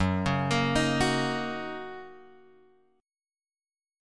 F#6 chord {2 4 x 3 4 2} chord